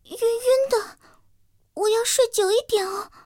T-127中破修理语音.OGG